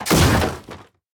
destroy3.ogg